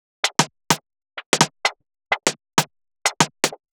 Index of /musicradar/uk-garage-samples/128bpm Lines n Loops/Beats
GA_BeatEnvC128-06.wav